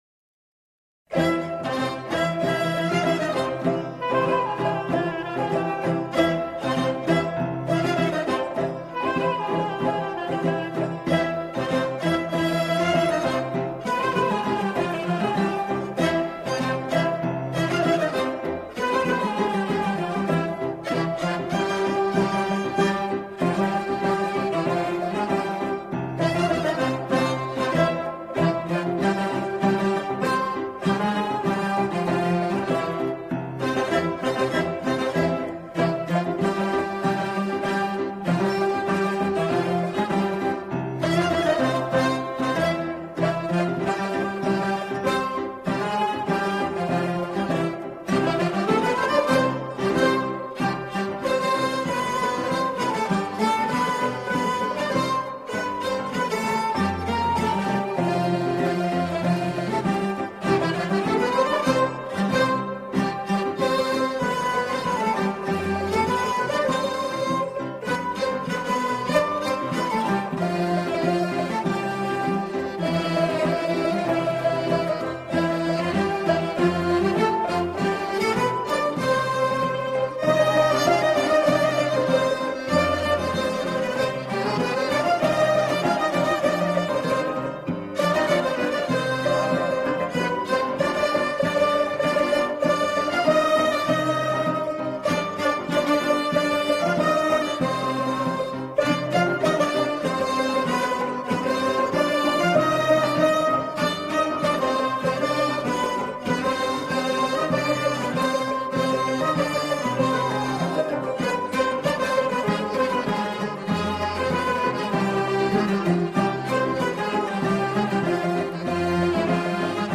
سرودهای ماه رمضان
خوانندگان، این قطعه را با شعری به گویش آذری اجرا می‌کنند.